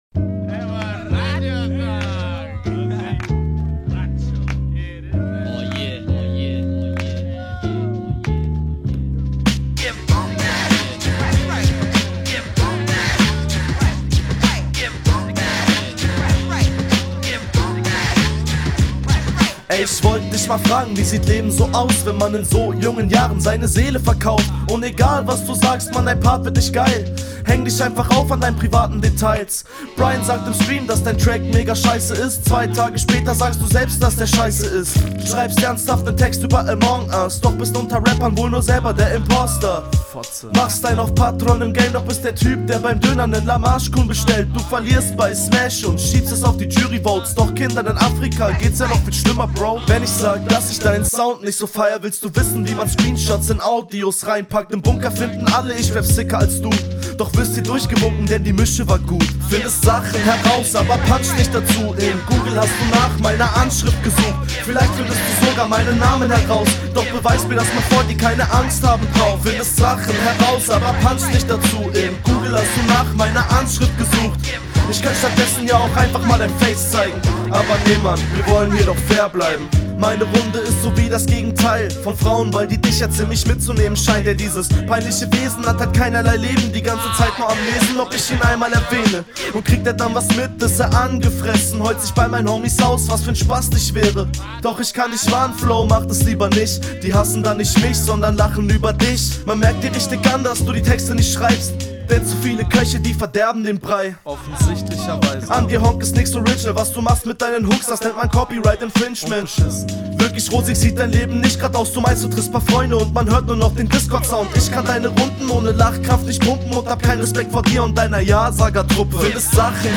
amongus sehr naheliegend. cooler beat. discord sound- gut. der sound und hörgenuss gefällt mir hier …